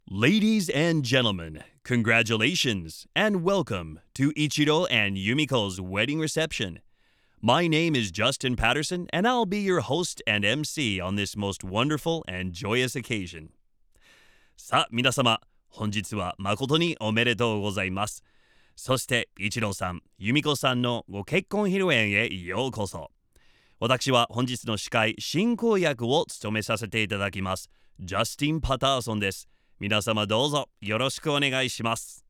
バイリンガル司会・MC
ボイスサンプル
ウエディングパーディー　MC
Wedding.wav